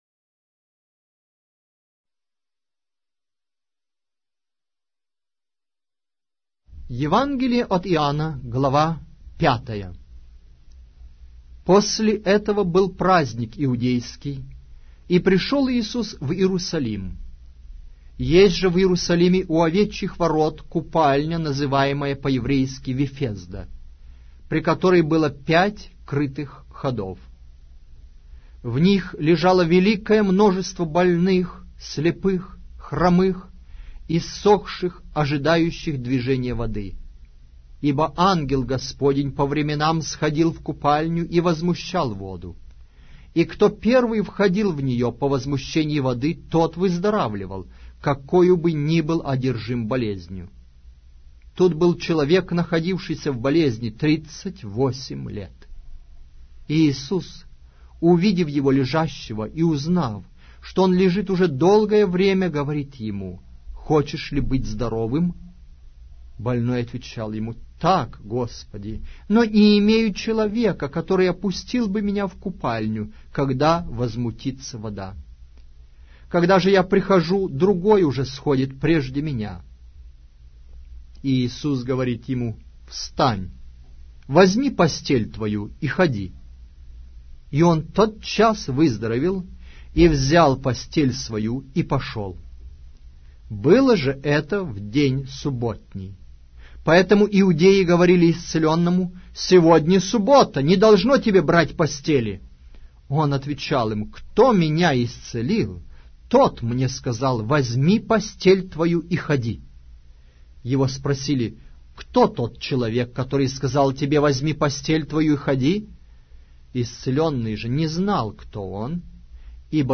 Аудиокнига: Евангелие от Иоанна